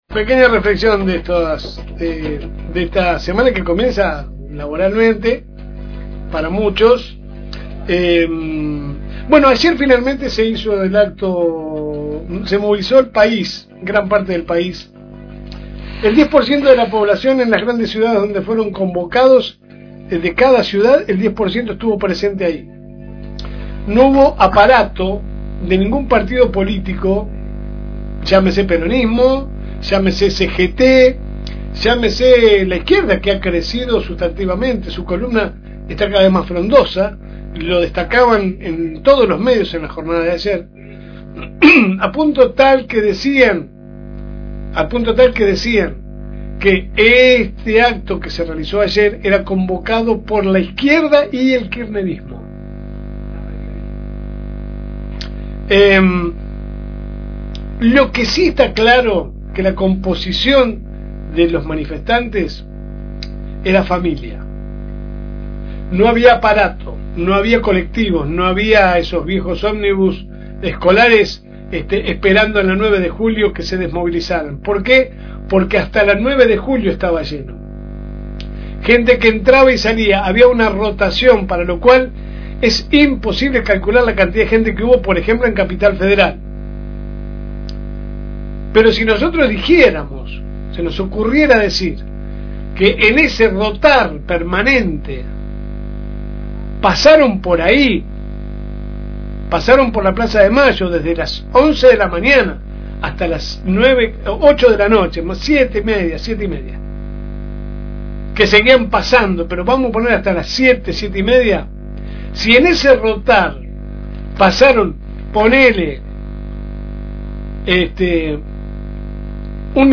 Compartimos la editorial/pequeño comentario